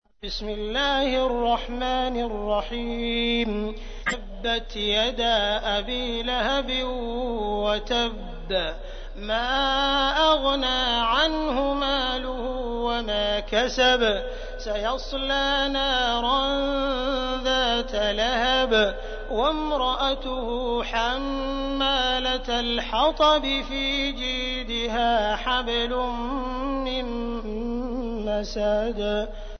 تحميل : 111. سورة المسد / القارئ عبد الرحمن السديس / القرآن الكريم / موقع يا حسين